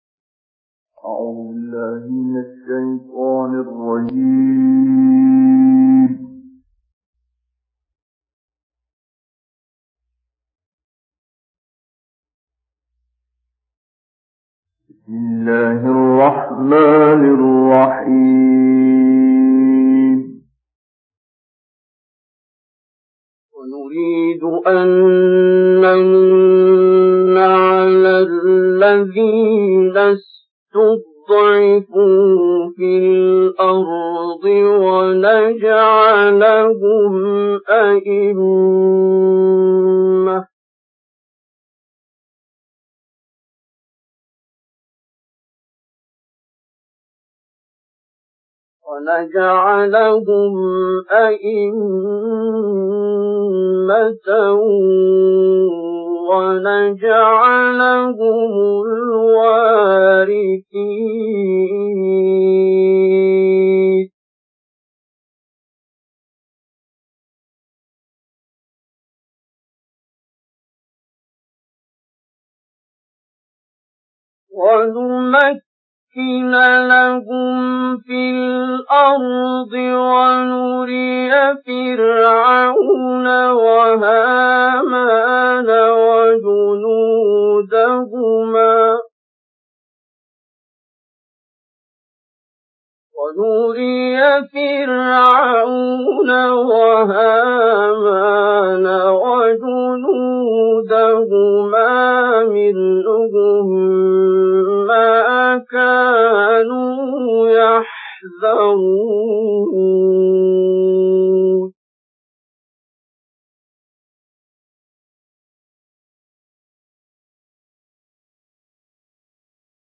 আব্দুল বাসিতের সুললিত কণ্ঠে কুরআন তিলাওয়াত
বিশ্ব বিখ্যাত ক্বারি আব্দুল বাসিত জেদ্দার রেডিওতে পবিত্র কুরআনের সূরা কাসাসের ৫ থেকে ২৪ নম্বর আয়াত পর্যন্ত তিলাওয়াত করেছেন।
আব্দুল বাসিতের সূরা কাসাসের ৫ থেকে ২৪ নম্বর আয়াতের তেলাওয়াতটি ১৯৫১ সালে জেদ্দায় রেকর্ডিং করা হয়েছে।